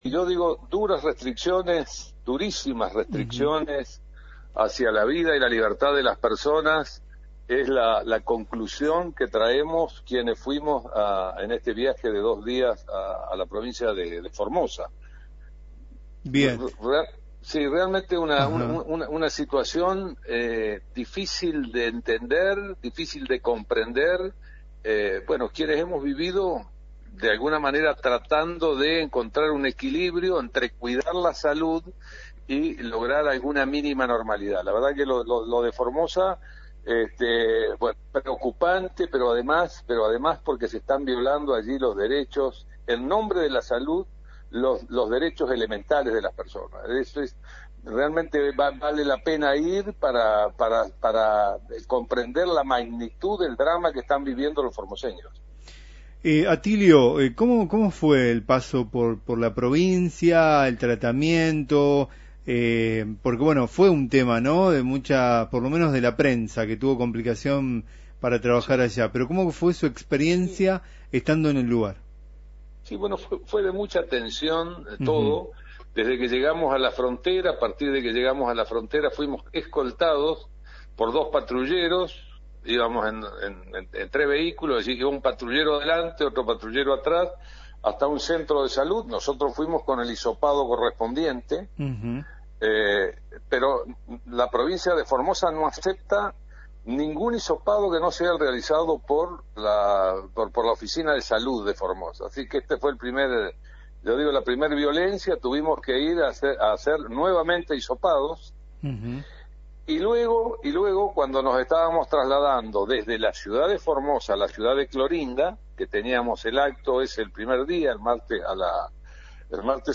El legislador Atilio Benedetti habló en “la 39” sobre su experiencia en las tierras de Gildo Insfran – Lt39 Noticias
Entrevistas